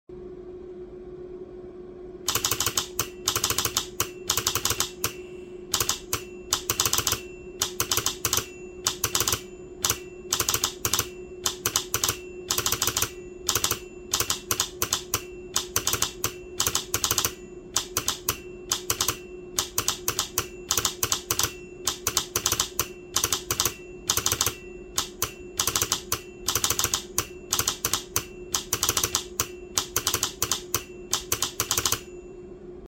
Telegraph Sounder A Z sound effects free download